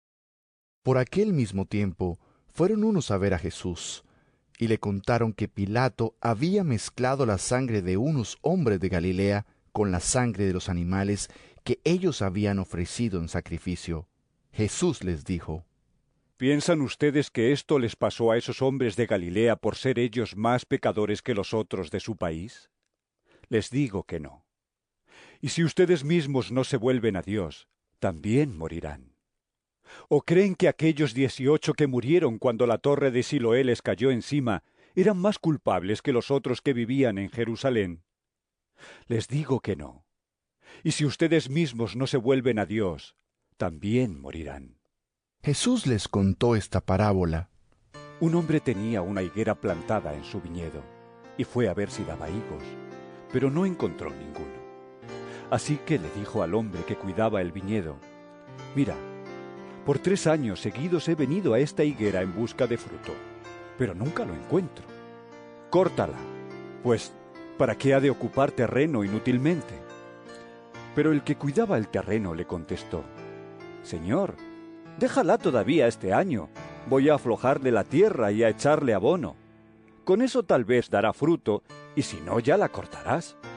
Lc 13 1-9 EVANGELIO EN AUDIO